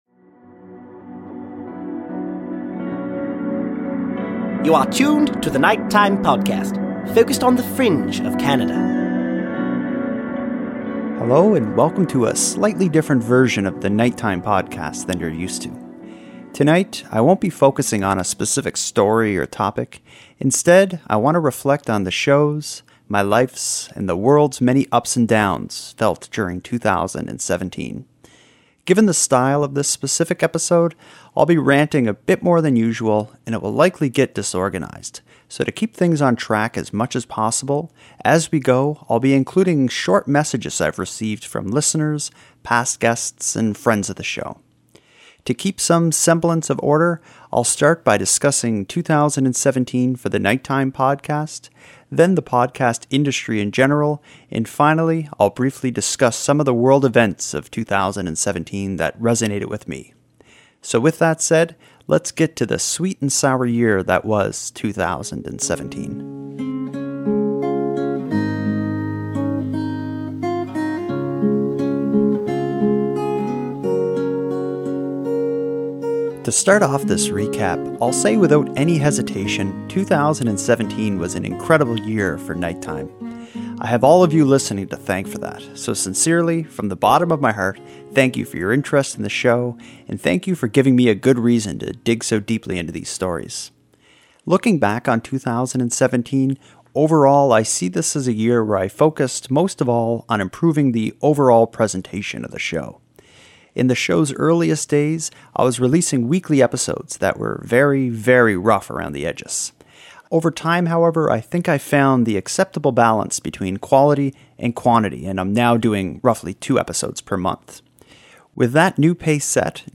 Throughout the episode I have included messages from listeners, friends of the show, and past Nighttime guests.